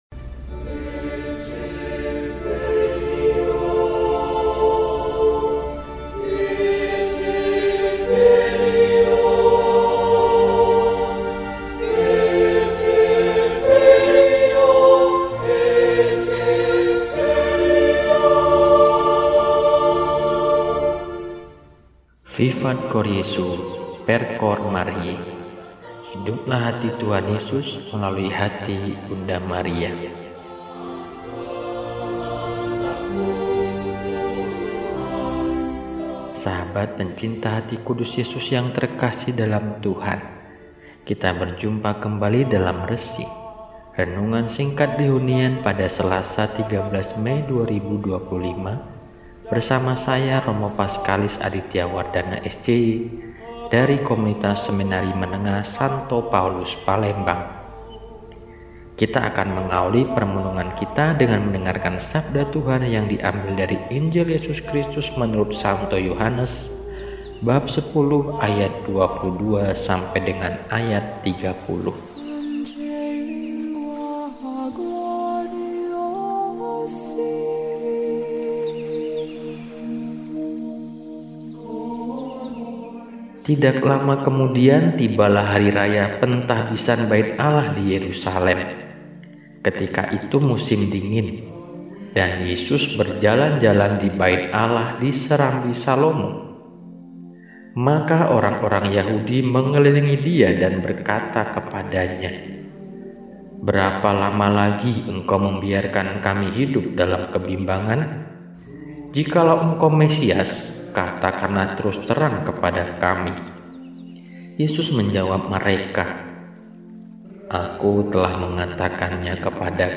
Selasa, 13 Mei 2025 – Hari Biasa Pekan IV Paskah – RESI (Renungan Singkat) DEHONIAN